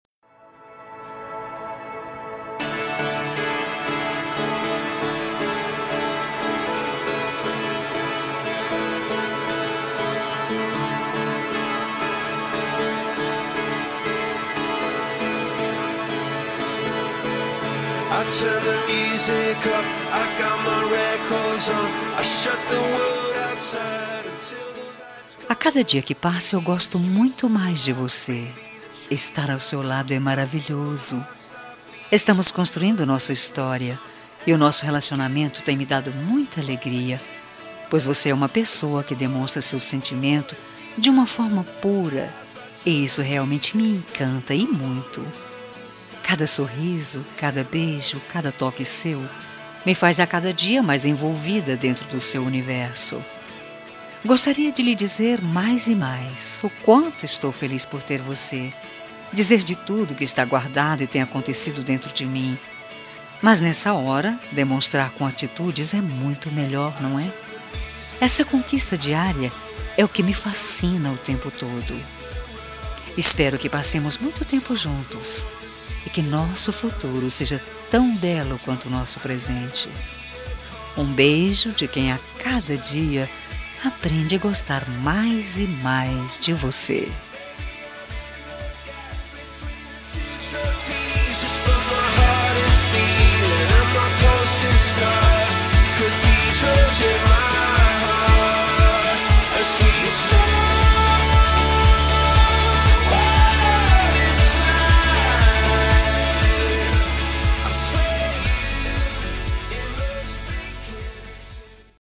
Telemensagem Romântica – Voz Feminina – Cód: 202012